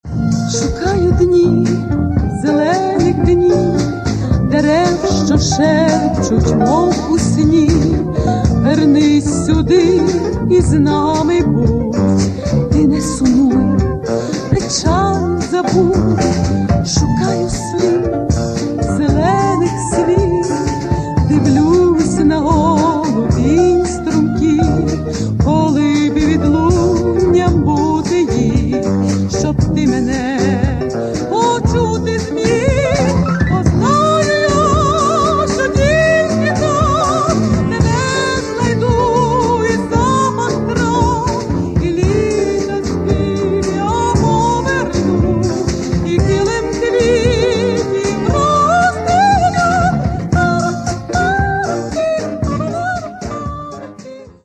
Каталог -> Эстрада -> Певицы
К сожалению, качество записи некоторых песен не безупречно.